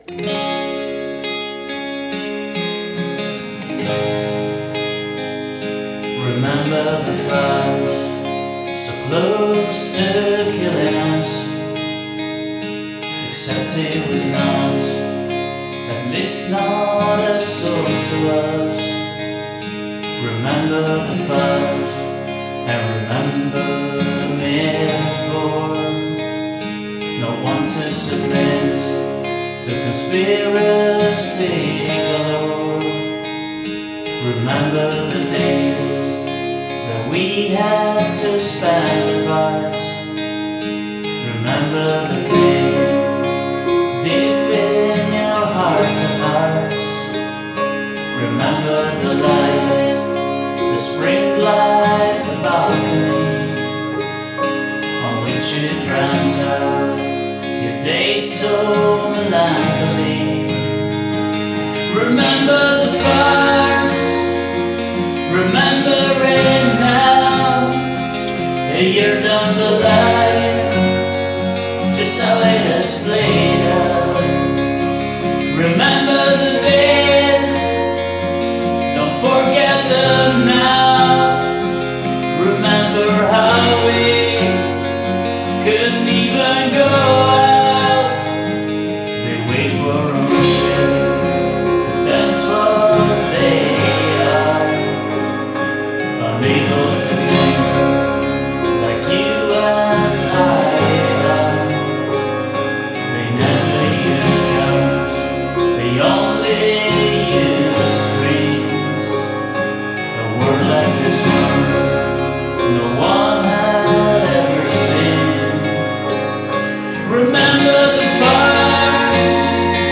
vocals, guitars, bass, keyboards, percussion